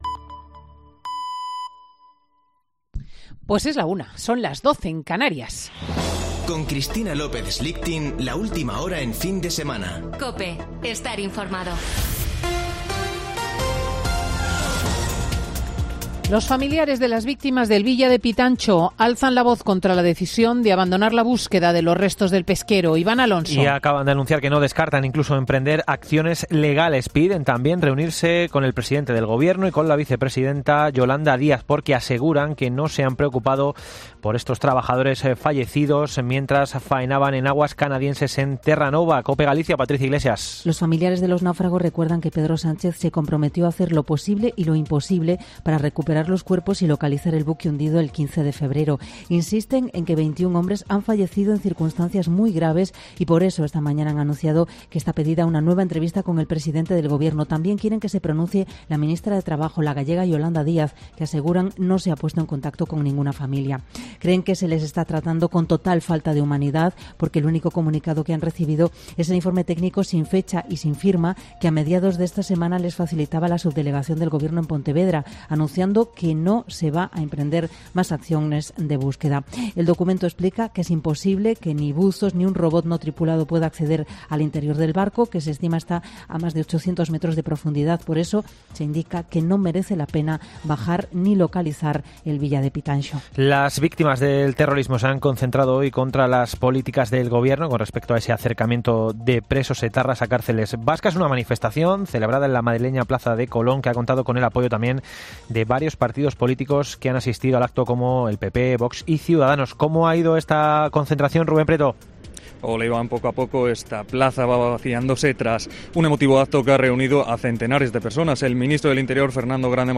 Boletín de noticias COPE del 26 de marzo de 2022 a las 13.00 horas